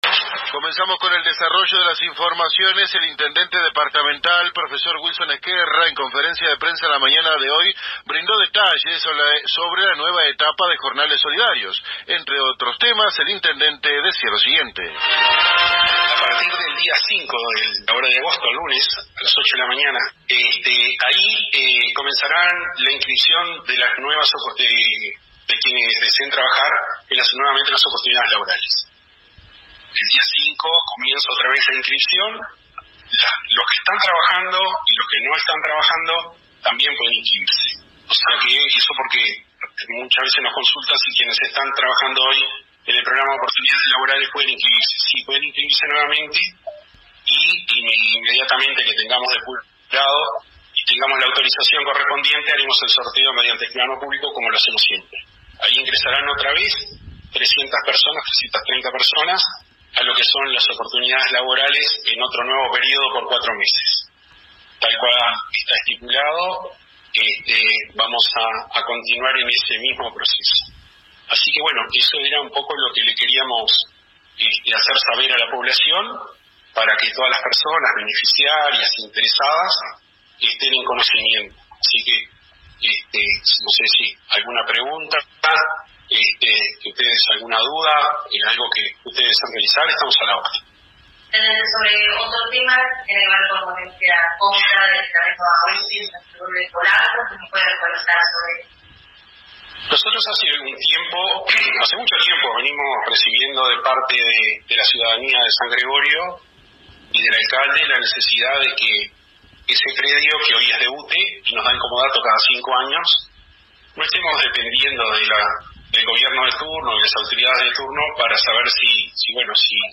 El Intendente Wilson Ezquerra en conferencia con los medios departamentales se pronunció sobre la compra de los terrenos de la península de San Gregorio de Polanco y que pertenecen a UTE, con la finalidad de ofrecer más oportunidades de desarrollo para la comunidad polanqueña, a la vez de brindar mayores y mejores servicios turísticos para los visitantes.